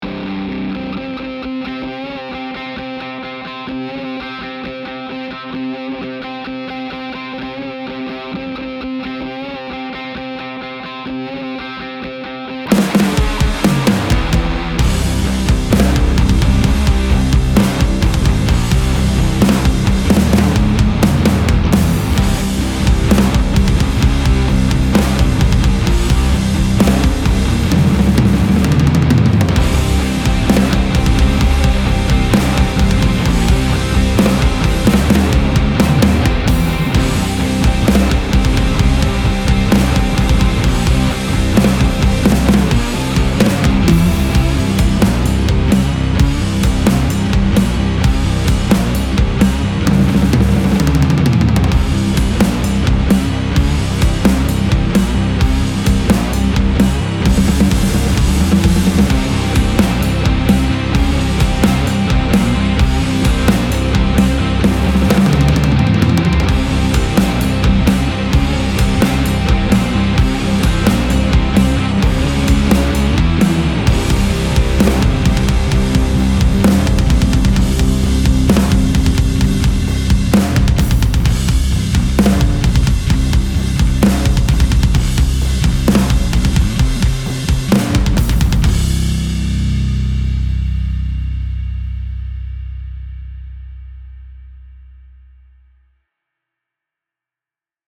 • ダブル・キック・ドラムを真のステレオ・ポジショニングで収録
獰猛で、巨大なドラムをより強く叩く
ドラムがギターの壁を切り裂く。